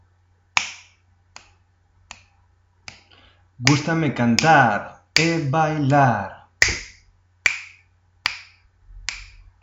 Agora, é momento de escoitar como quedaría este mesmo esquema rítmico se lle engadimos a letra que acabamos de ver nos dous exemplos.